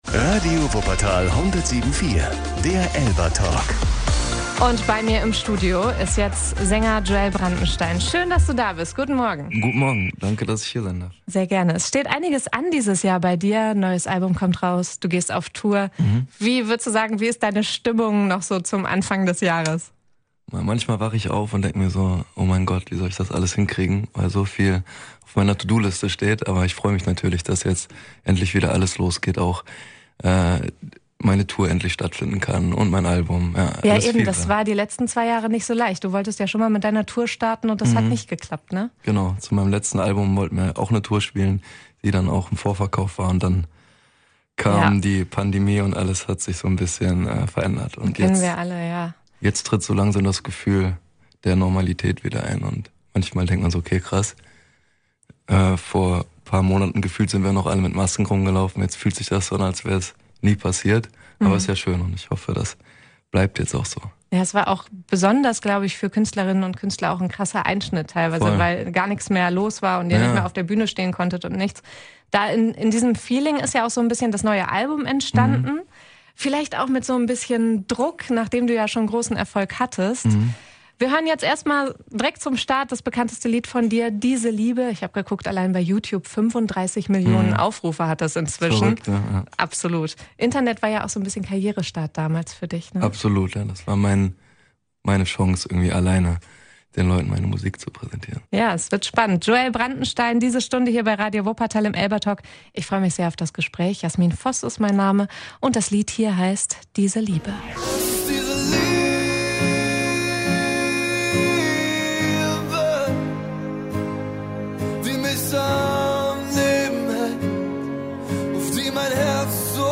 Hört euch das Interview und erste Musikausschnitte hier an!